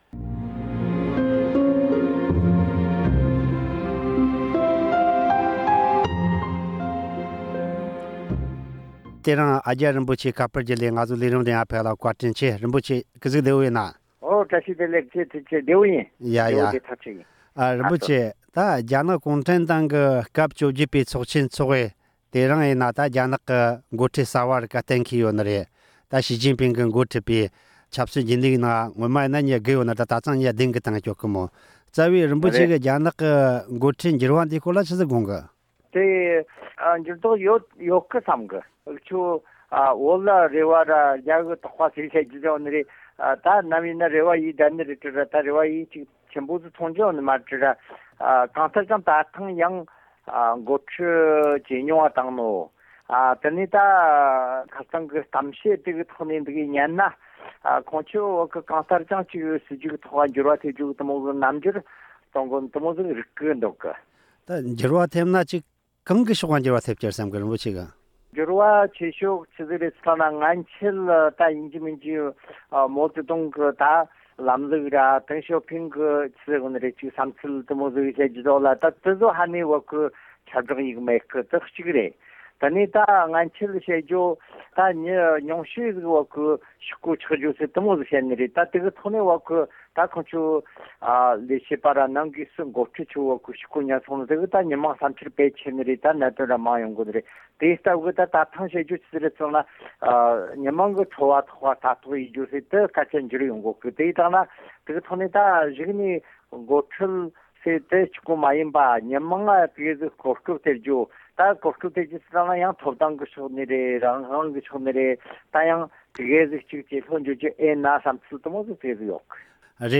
རྒྱ་ནག་གི་མཐོ་རིམ་འགོ་ཁྲིད་འཕོ་འགྱུར་བྱུང་བ་དེས་མ་འོངས་པར་རྒྱ་ནག་དང་བོད་ནང་དུ་འགྱུར་བ་ཐེབས་མིན་སོགས་ཀྱི་ཐད་གླེང་མོལ།